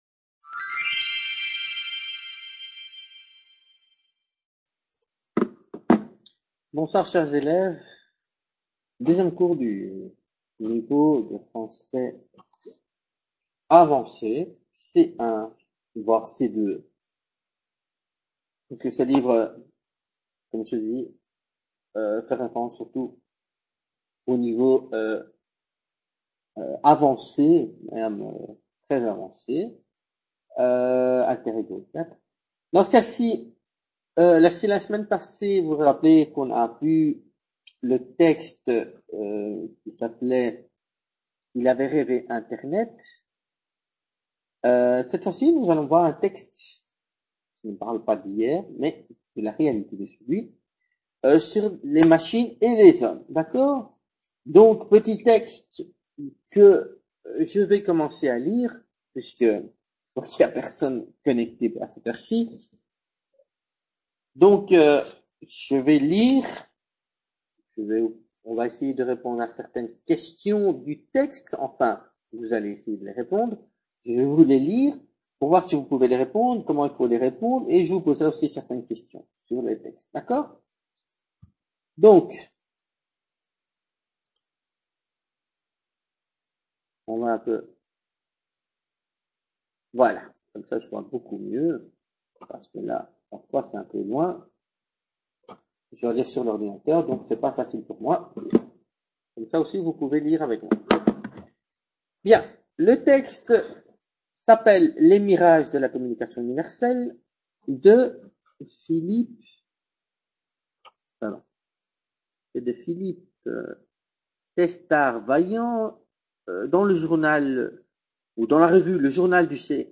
Clase de Francés Nivel Avanzado 19/11/2015 | Repositorio Digital